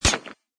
metalstone.mp3